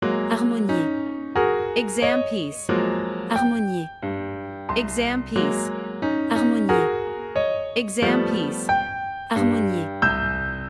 Professional-level Piano Exam Practice Materials.
• Vocal metronome and beats counting
• Master performance examples